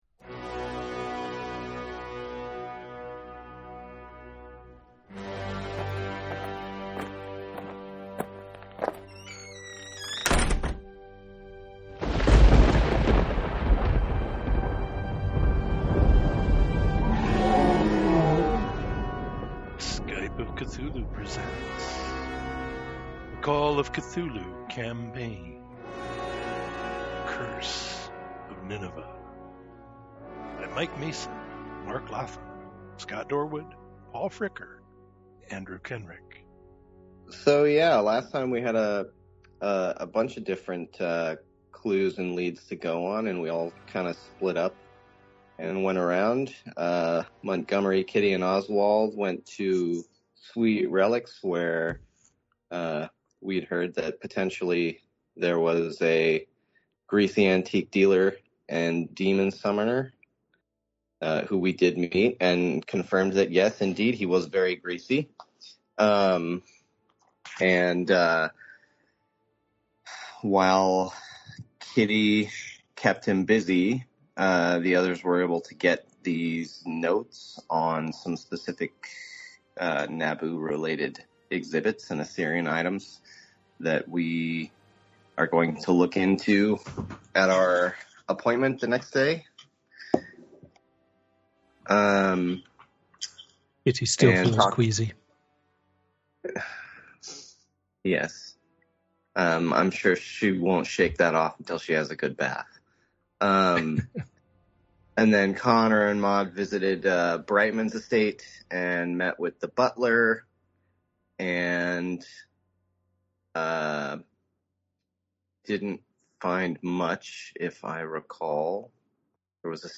Skype of Cthulhu presents a Call of Cthulhu scenario.